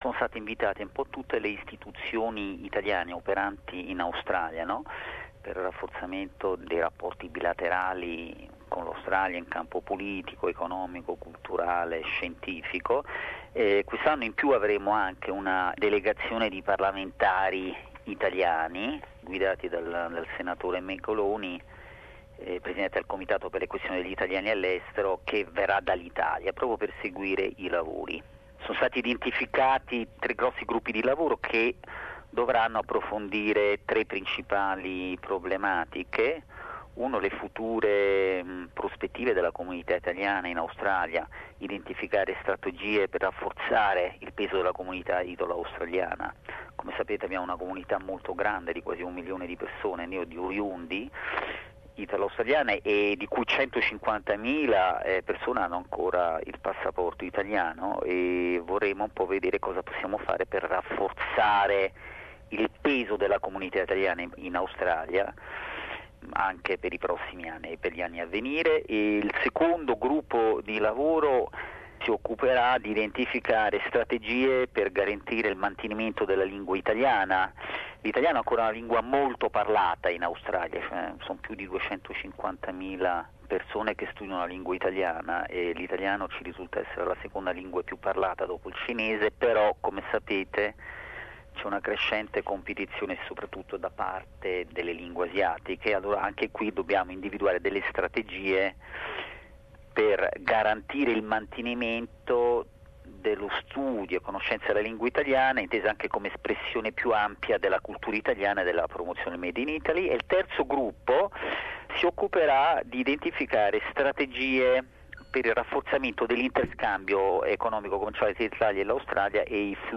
Tra le tematiche che verranno affrontate c'è anche quella che riguarda il mantenimento e la diffusione della lingua e della cultura italiana down under. Ne abbiamo parlato con l'ambasciatore italiano in Australia Pier Francesco Zazo.